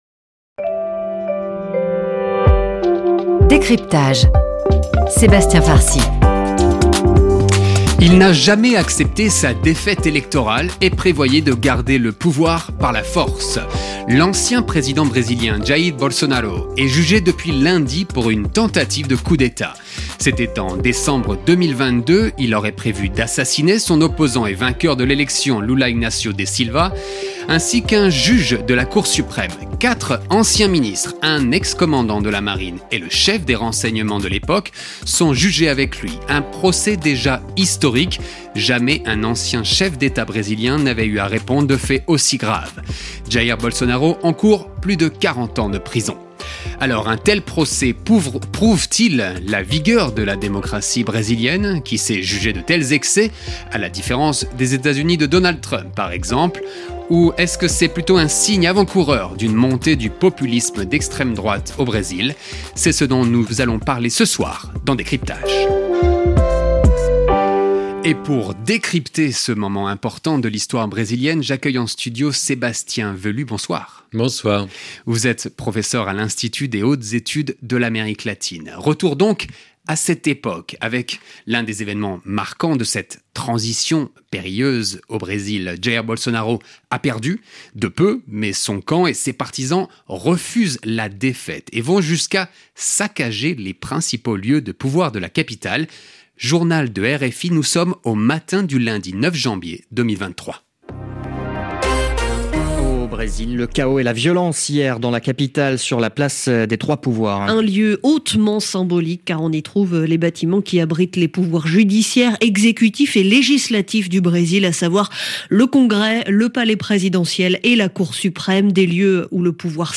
Le décryptage du fait d’actualité du jour avec un à trois spécialistes invités pour contextualiser, expliquer et commenter.